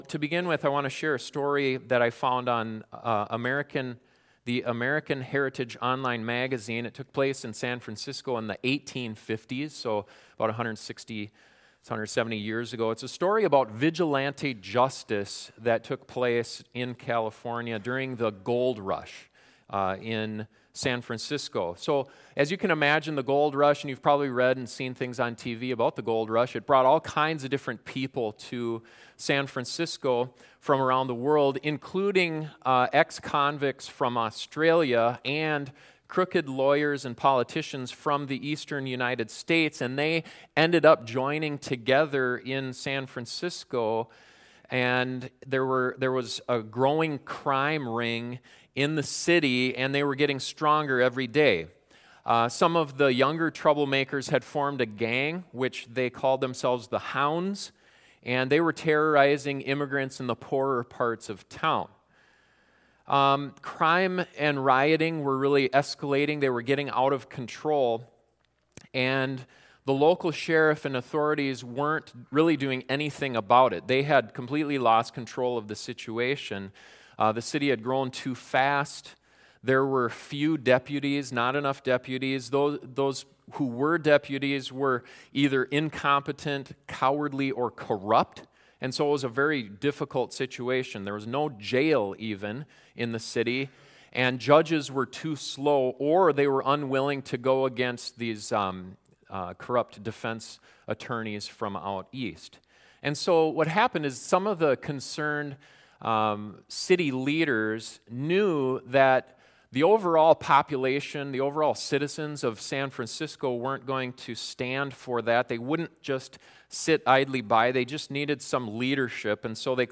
Is there anything you can do to bring about justice? This sermon looks into one of the most common things people do in those situations and whether or not it’s ok for Christians to do so.